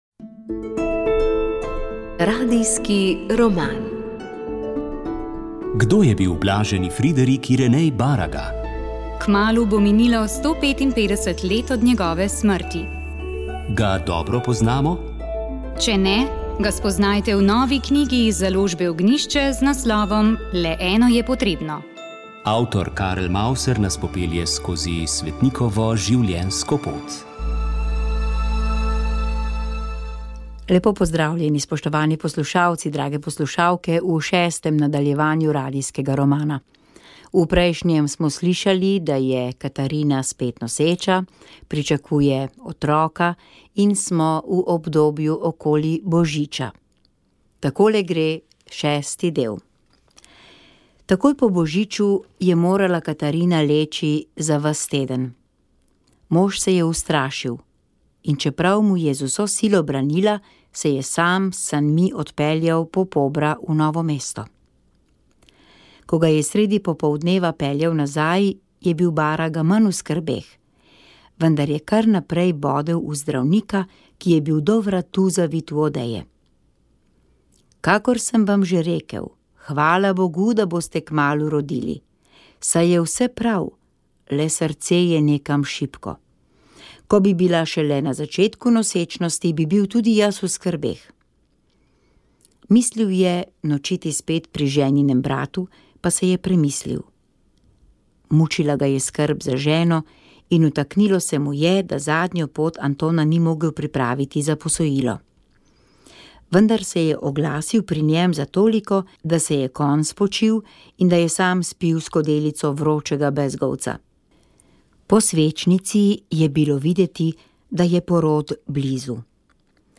Radijski roman